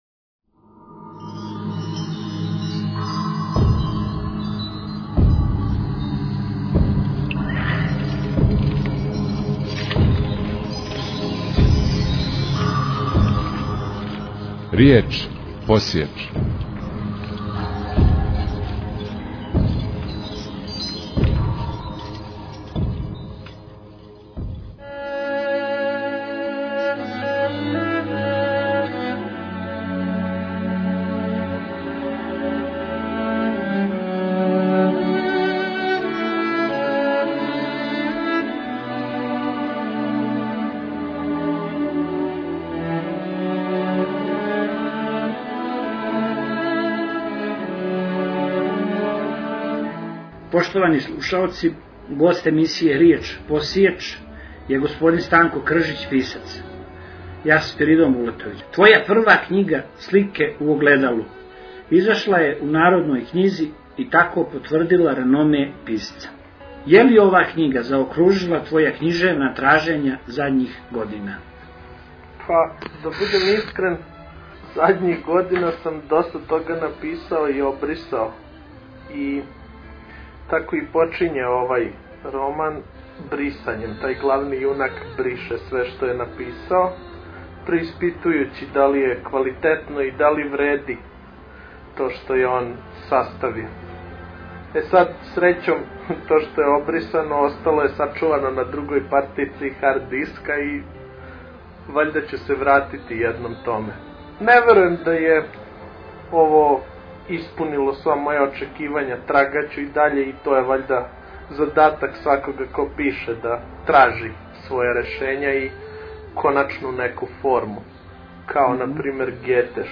Формат: MP3 Mono 11kHz 20Kbps (VBR)